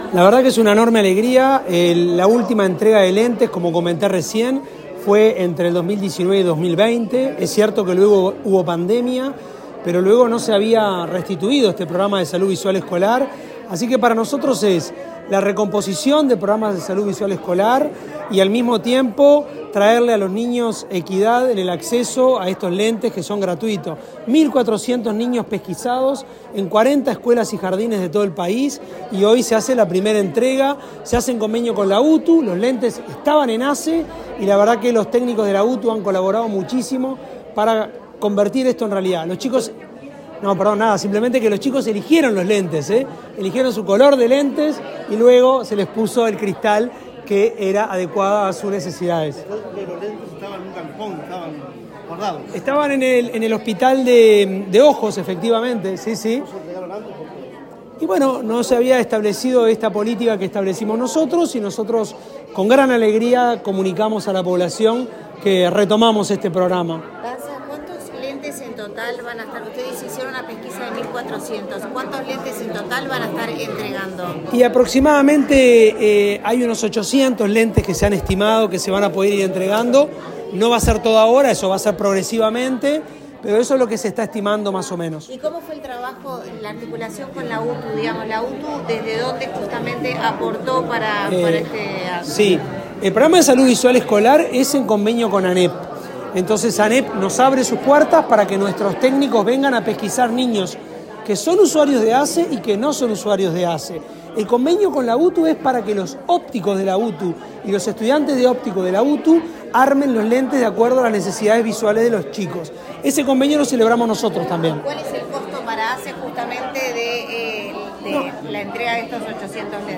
Declaraciones del presidente de ASSE, Álvaro Danza
Las declaraciones fueron realizadas durante una nueva jornada de entrega de lentes en el marco del programa.